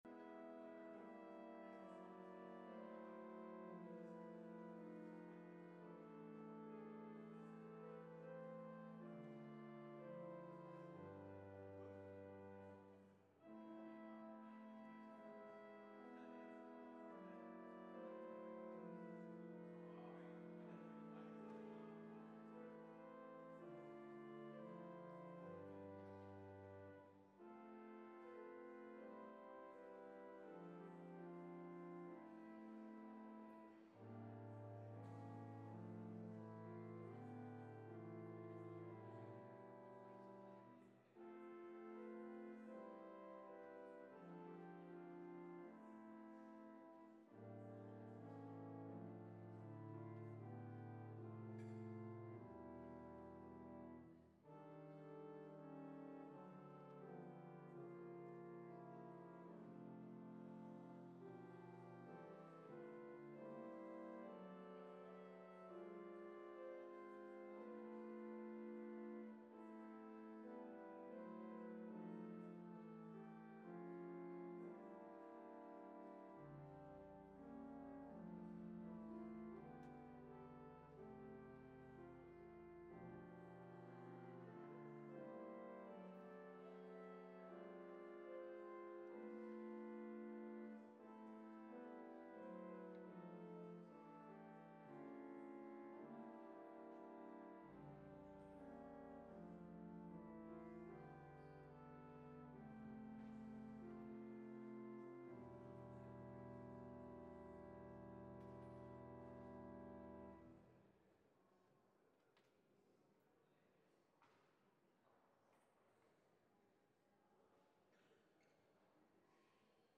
Sermon Only Audio